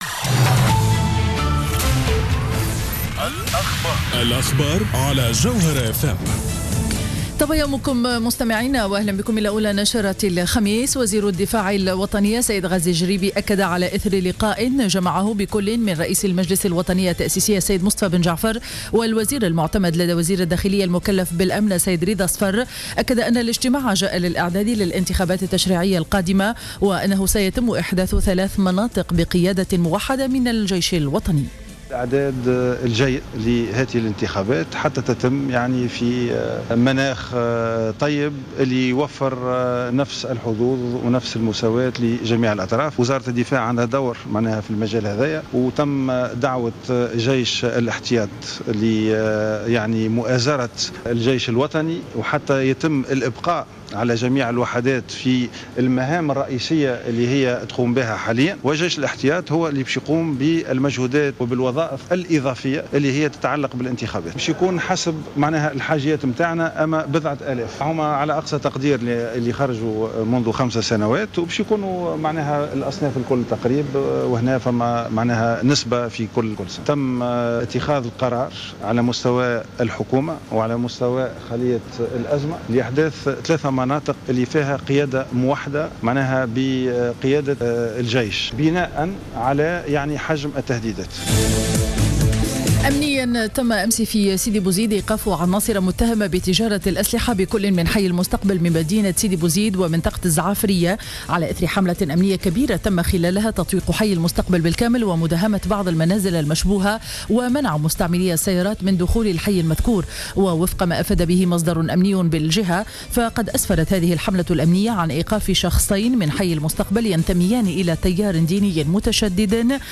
نشرة أخبار السابعة صباحا ليوم الخميس 04-09-14